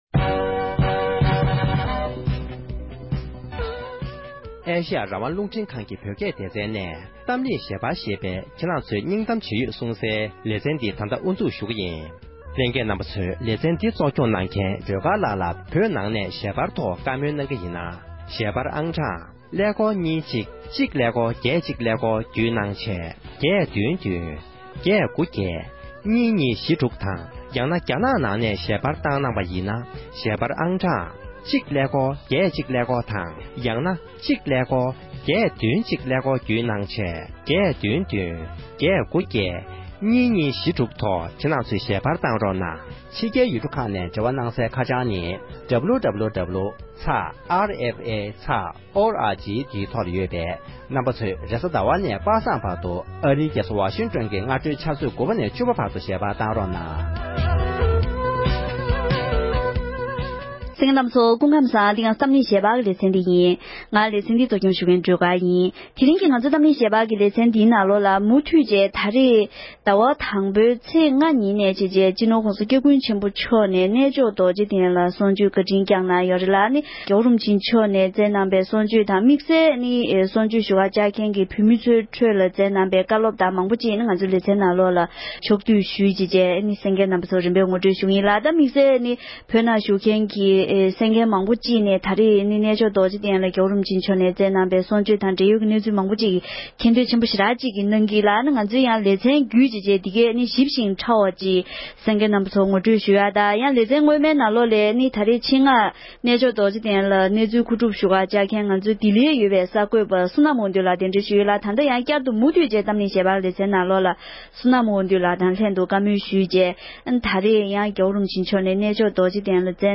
༄༅༎དེ་རིང་གི་གཏམ་གླེང་ཞལ་པར་གྱི་ལེ་ཚན་ནང་འདི་ཟླའི་ཚེས་ལྔ་ཉིན་ནས་ཉིན་གྲངས་བཞིའི་རིང་རྒྱ་གར་གནས་མཆོག་རྡོ་རྗེ་གདན་དུ་བོད་མིའི་བླ་ན་མེད་པའི་དབུ་ཁྲིད་སྤྱི་ནོར་༸གོང་ས་༸སྐྱབས་མགོན་ཆེན་པོ་མཆོག་ནས་དད་ལྡན་ཆོས་ཞུ་བ་ལྔ་ཁྲི་ལྷ་ལ་གསུང་ཆོས་བཀའ་དྲིན་སྐྱངས་གནང་མཛད་ཡོད་པའི་ཁྲོད་ནས་དམིགས་བསལ་བཀའ་སློབ་ཁག་ཕྱོགས་བསྡུས་ཞུས་པའི་དུམ་མཚམས་གསུམ་པ་དེར་གསན་རོགས༎